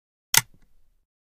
switch.ogg